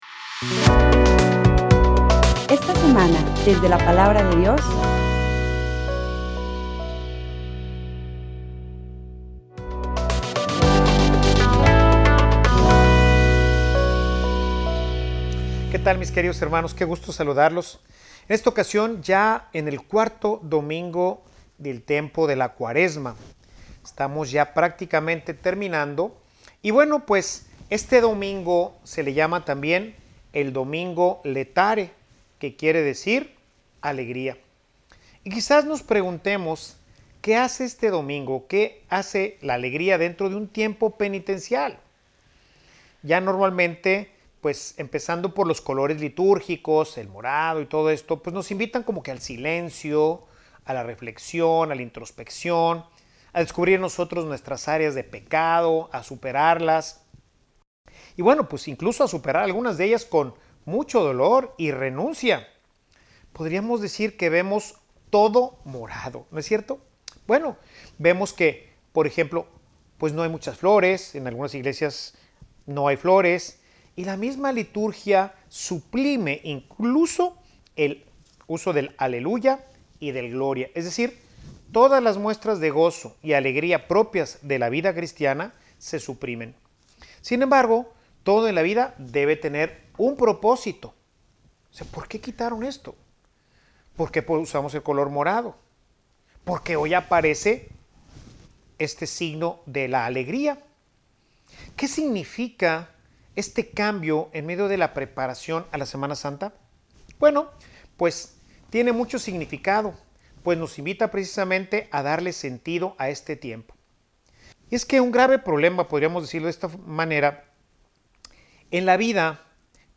Homilia_Una_vida_con_proposito_la_alegria_eterna.mp3